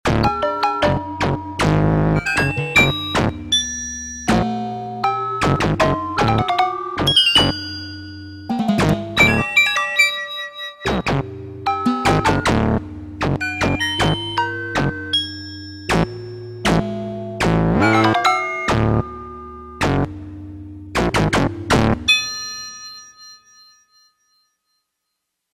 Class: synthesizer module
Synthesis: analog sub
factory demo 7